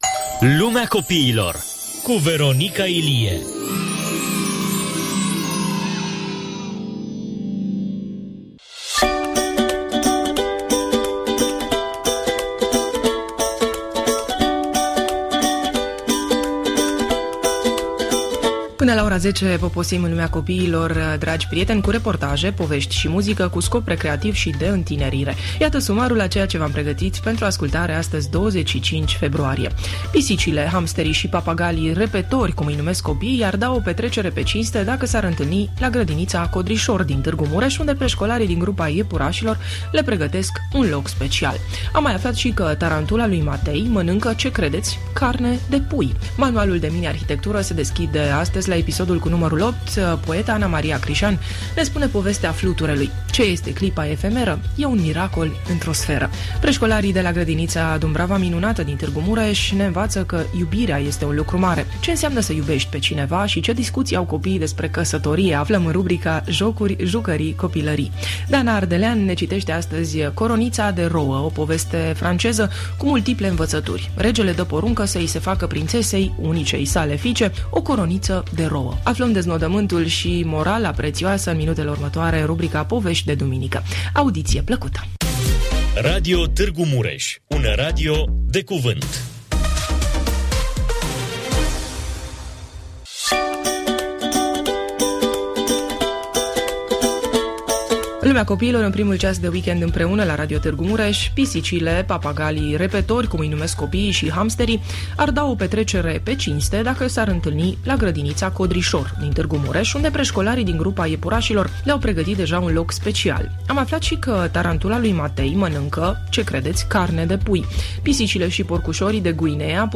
Dimineața de duminică începe cu o incursiune în „Lumea copiilor”, cu reportaje antrenante, povești interesante și muzică veselă. Pisicile, hamsterii și papagalii „repetori” ar da o petrecere pe cinste dacă s-ar întâlni la grădinița „Codrișor” din Târgu Mureș, unde preșcolarii din grupa „Iepurașilor” le pregătesc un loc special.